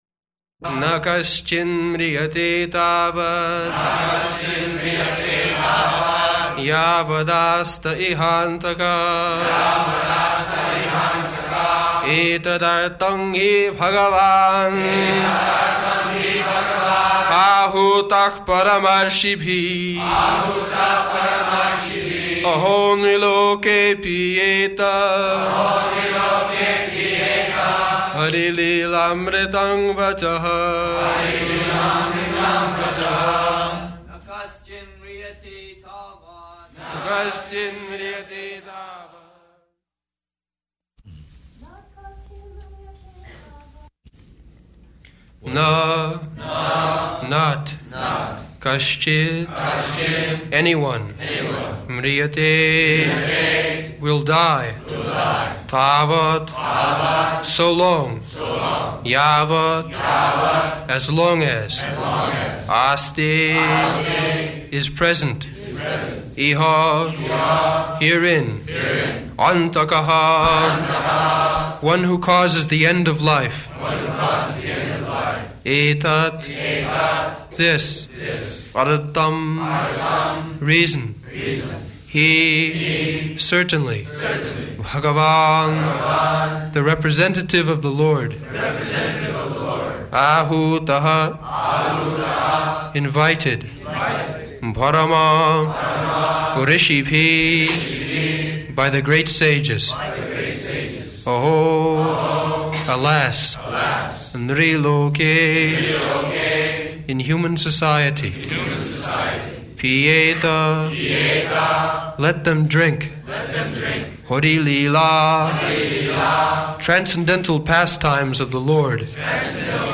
Location: Los Angeles
[Prabhupāda and devotees repeat]